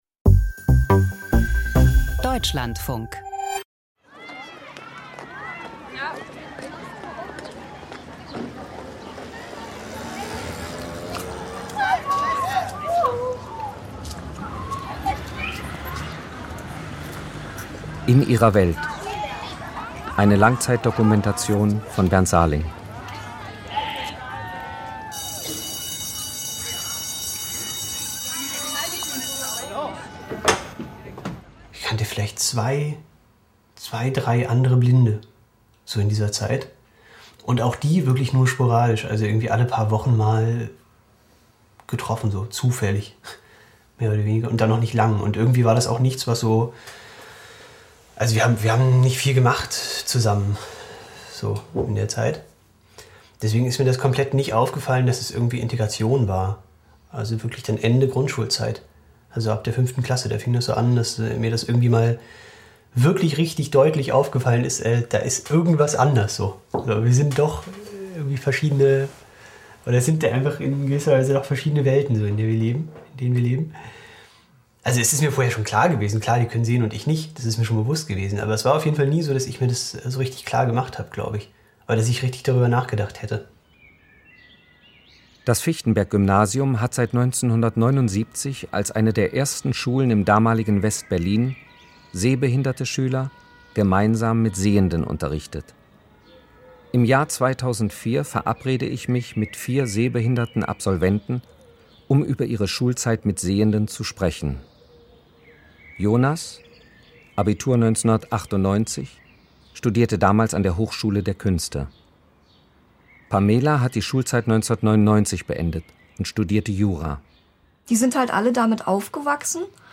Blinde und Sehende in einer Schule. Eine Langzeitbeobachtung.